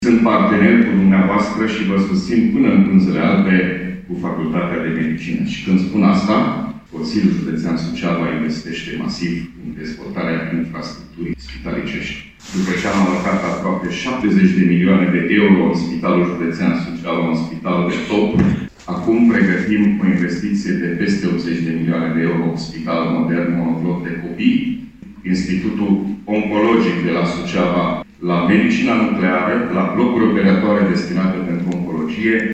Președintele Consiliului Județean GHEORGHE FLUTUR a mai precizat, la ședința festivă de la USV, că un alt proiect important în pregătire este cel al înființării Facultății de Medicină.